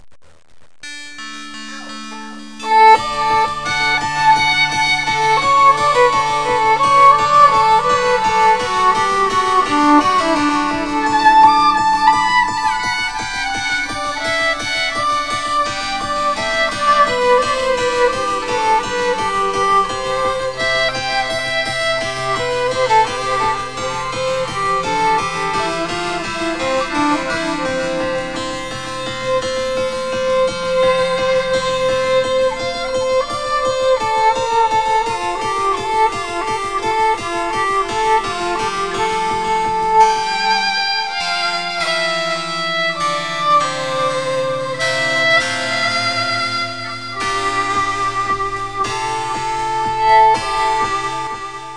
An instrumental recording.
One of the few recordings we have of her on violin.
Right near the start there's a faint sound I've always heard as someone saying "go." When I asked her about it, she insisted it was her cat.